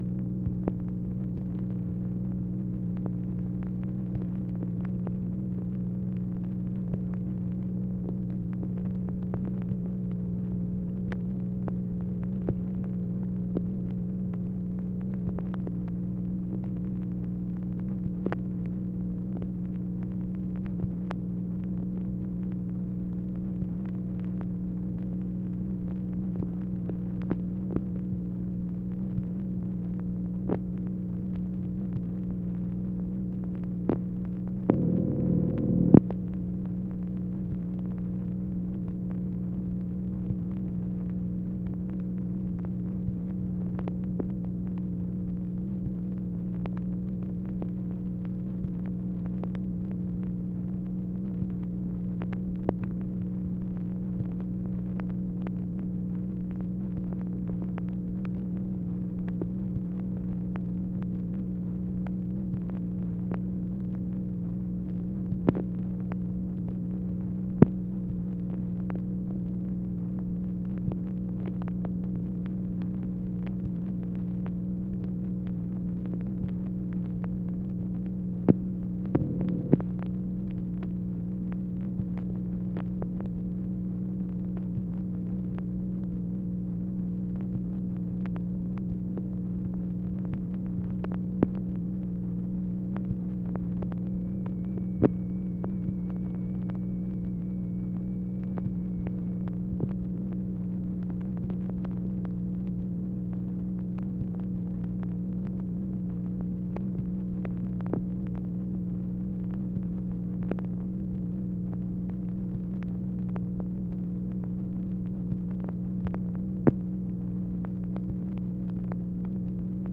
MACHINE NOISE, January 17, 1964
Secret White House Tapes | Lyndon B. Johnson Presidency